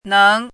怎么读
néng nài
neng2.mp3